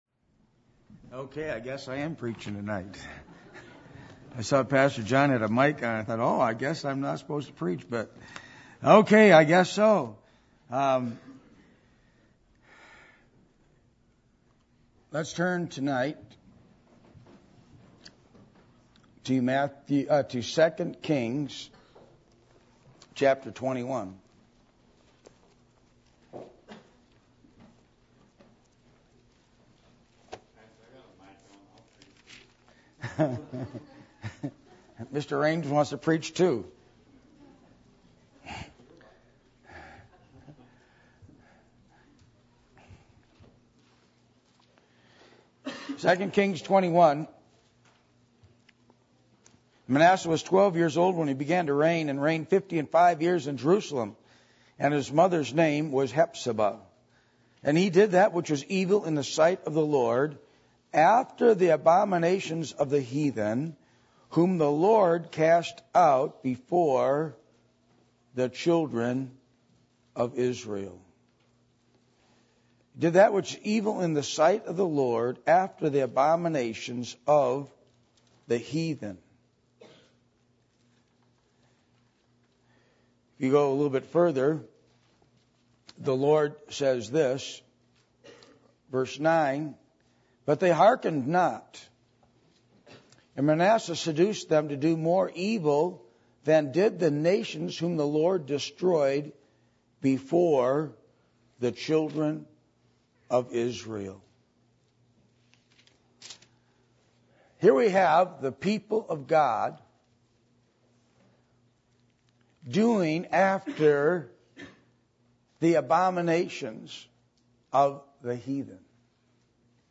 Passage: 2 Kings 21:1-9 Service Type: Sunday Evening %todo_render% « Sardis